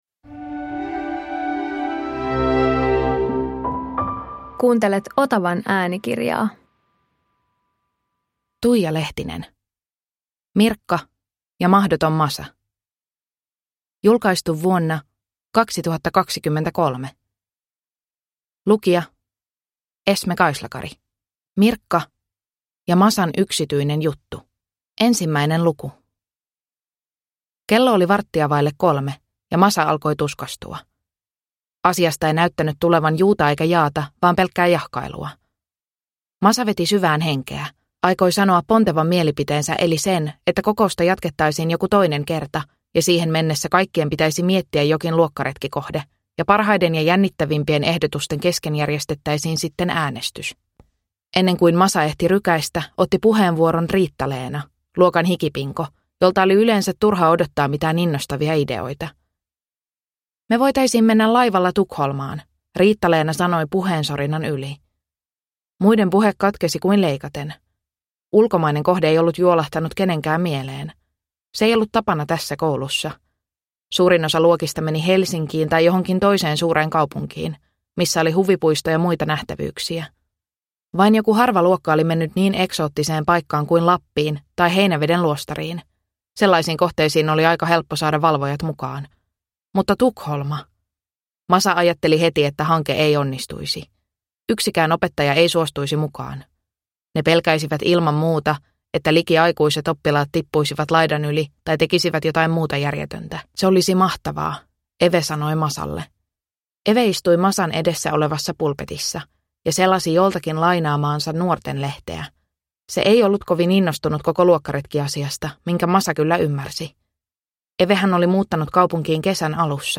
Äänikirja sisältää romaanit Mirkka ja Masan yksityinen juttu (1993) sekä Mirkka, Masa ja Miss USA (1994).